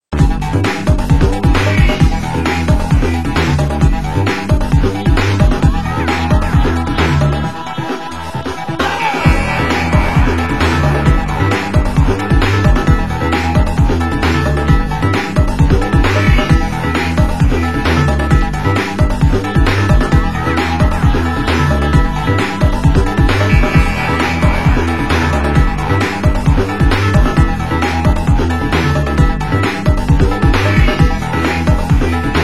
Genre: Nu Skool Breaks